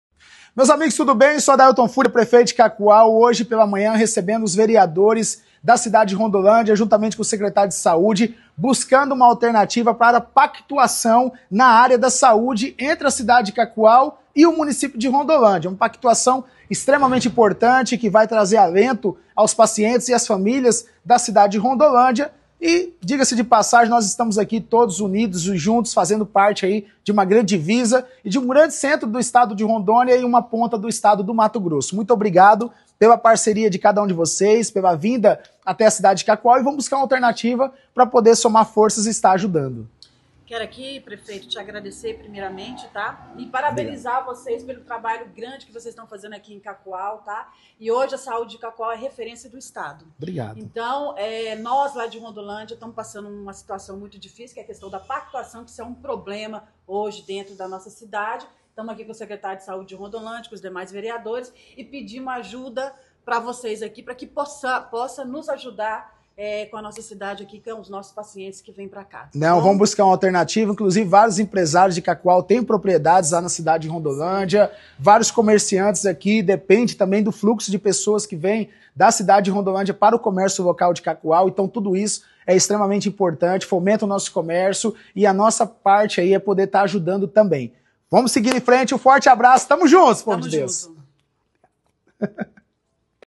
REUNIÃO DE VEREADORES DA CÂMARA MUNICIPAL DE RONDOLÂNDIA-MT COM O PREFEITO DE CACOAL ADAILTON FÚRIA